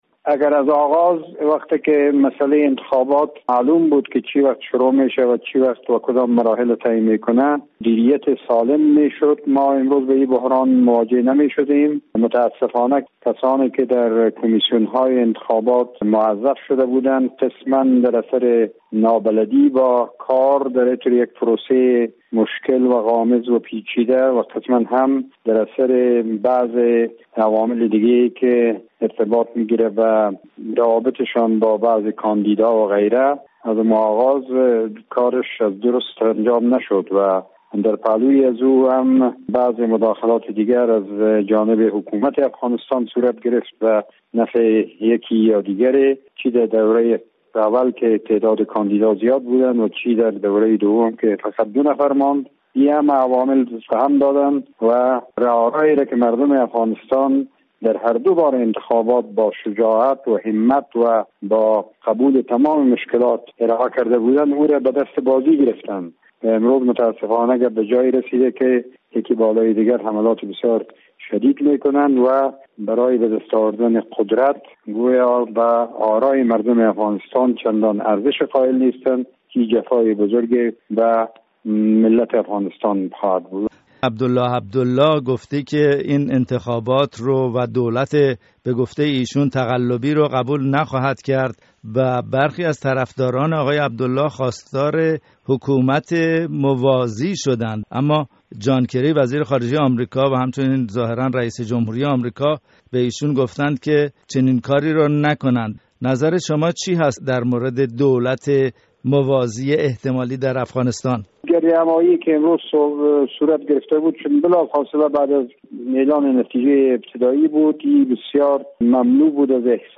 رادیو فردا در گفت وگو با امين فرهنگ، وزير اقتصاد و تجارت پيشين در دولت حامد کرزی، نظر او را درباره تحولات بحران انتخاباتی افغانستان پرسيده است.